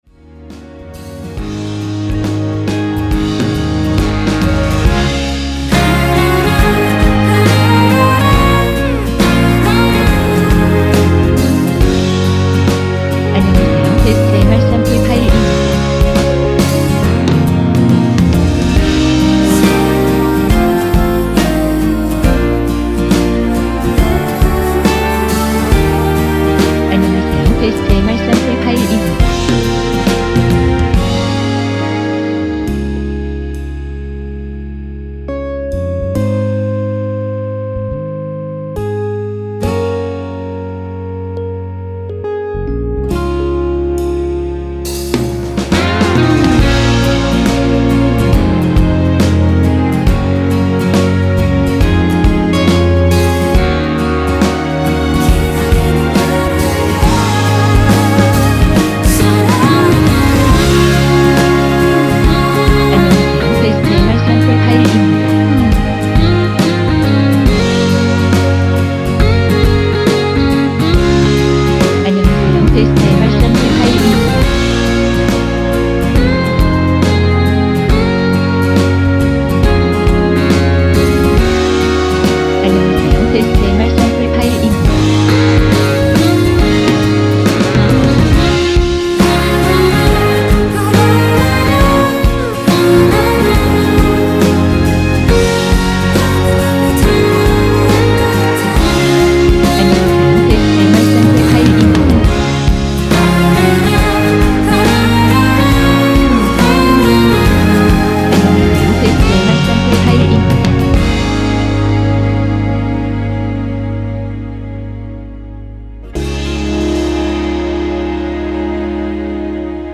전주 없이 시작하는 곡이라 전주 1마디 만들어 놓았습니다.(일반 MR 미리듣기 참조)
미리듣기에 나오는 부분이 코러스 추가된 부분 입니다.(미리듣기 샘플 참조)
원키 코러스 포함된 MR입니다.
Bb